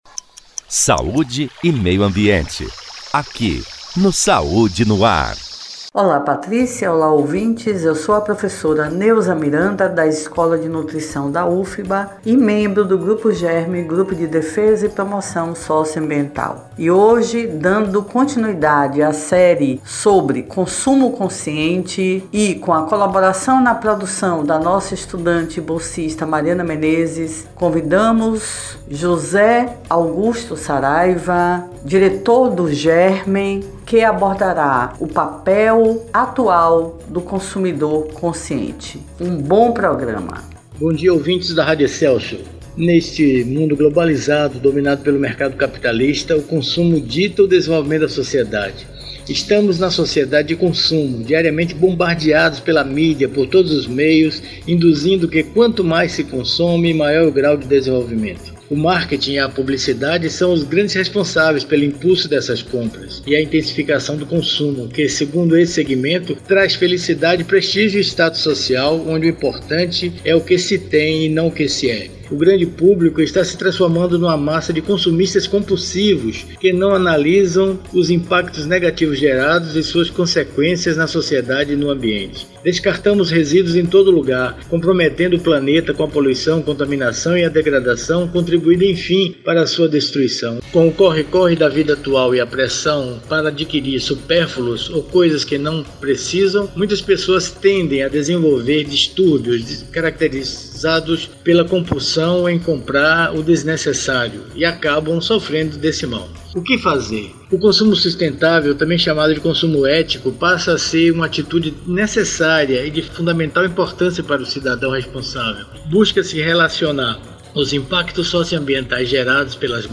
O assunto foi tema do quadro “Meio Ambiente e Saúde”, veiculado às Quartas-feiras pelo programa Saúde no ar, com transmissão pelas Rádios Excelsior AM 840  e  Web Saúde no ar.